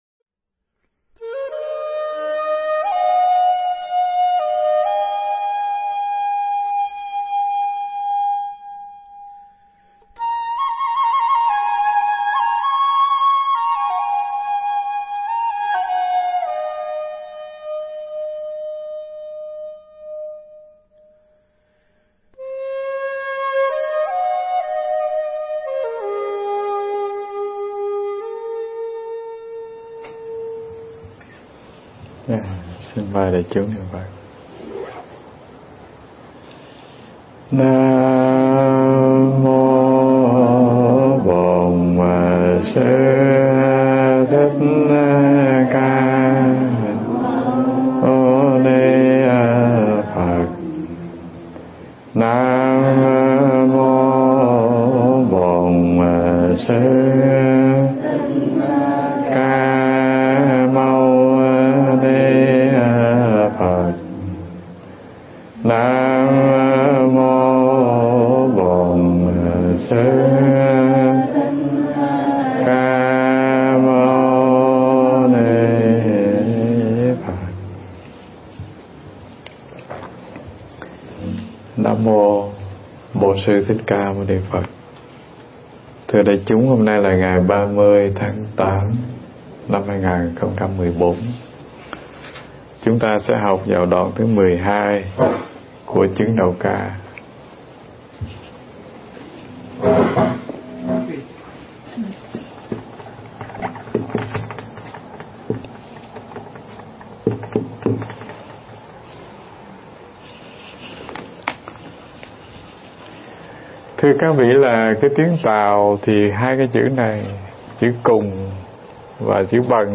Nghe Mp3 thuyết pháp Chứng Đạo Ca 14 Châu Báu Tự Thân